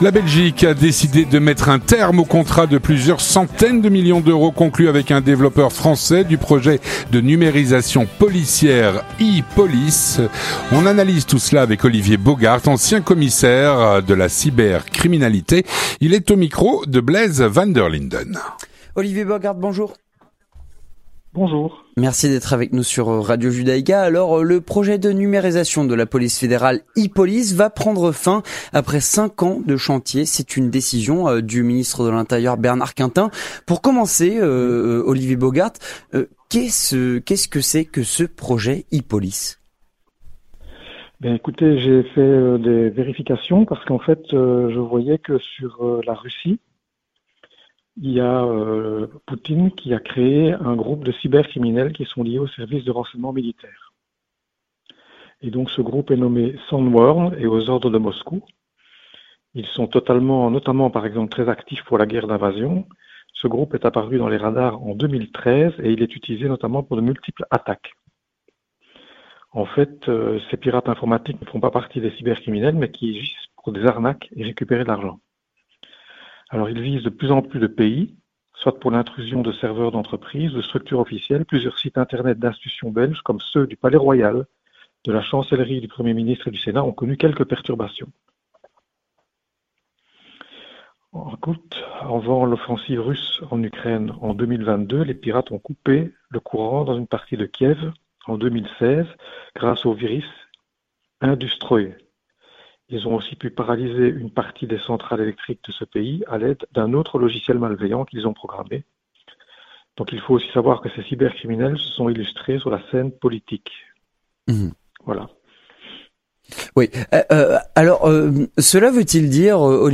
Il est au micro de